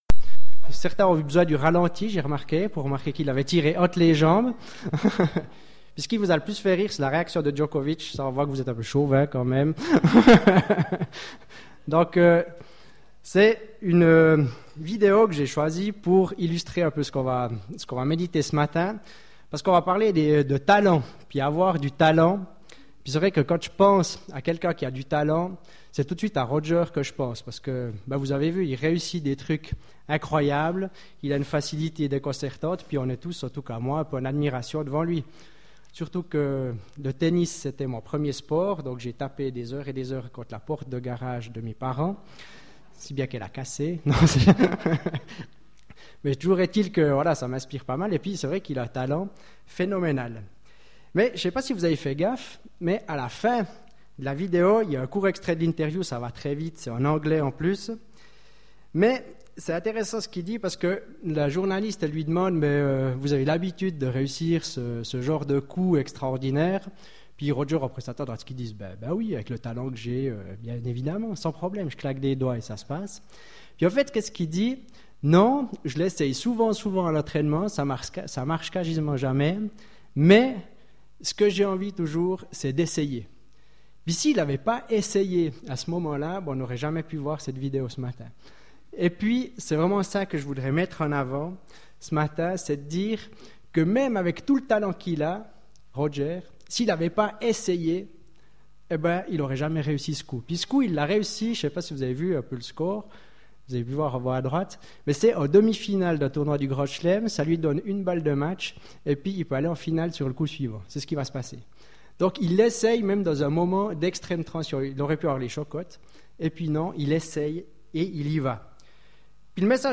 Culte du 29 mai 2016